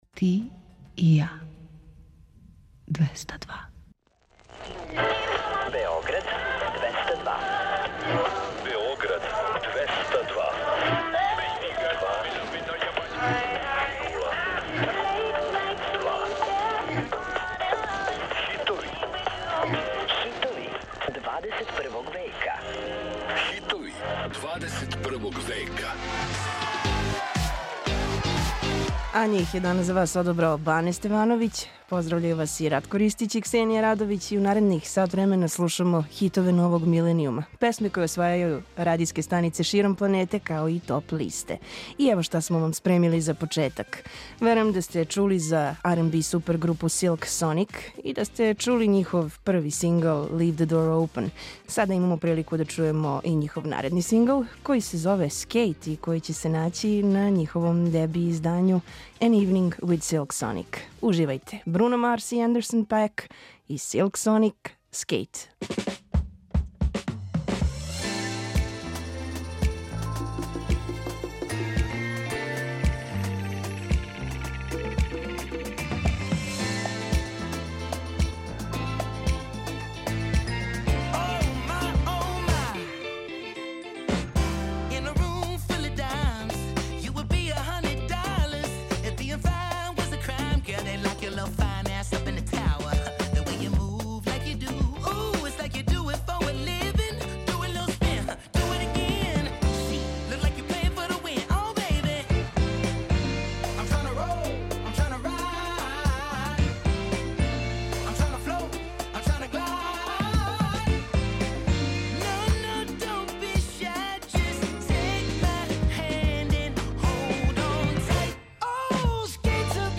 Slušamo hitove novog milenijuma, koje osvajaju top liste i radijske stanice širom planete.